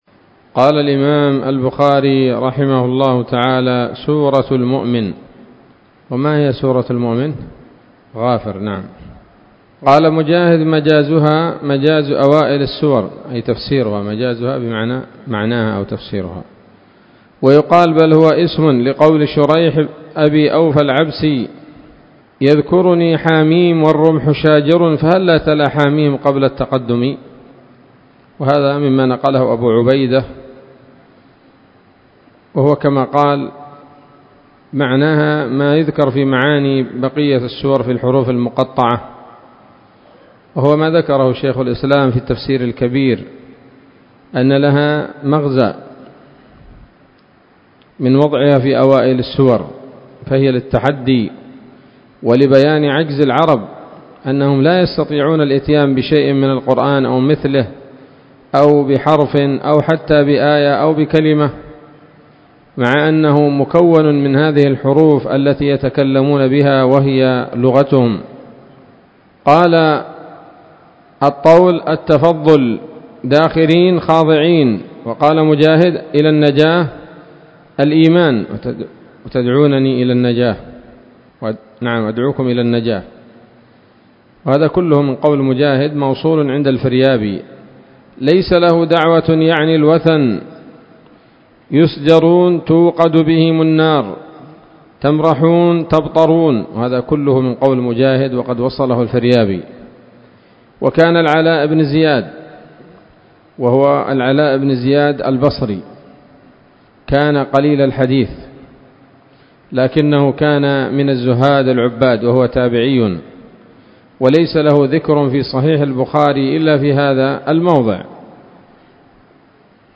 الدرس العشرون بعد المائتين من كتاب التفسير من صحيح الإمام البخاري